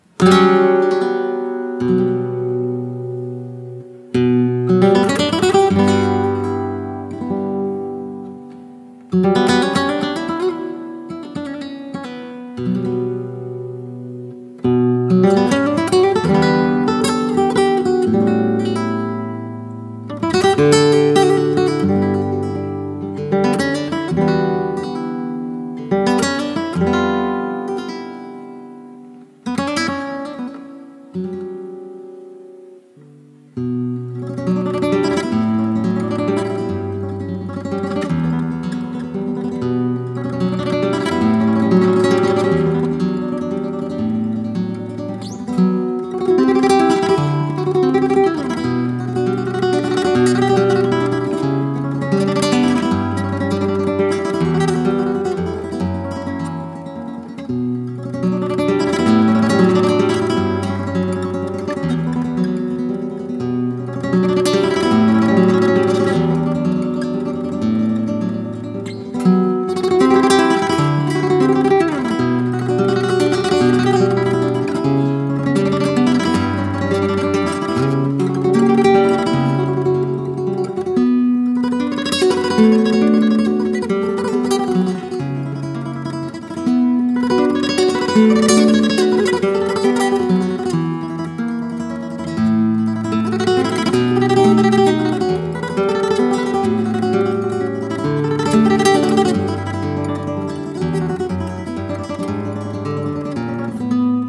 Zurück zu: Flamenco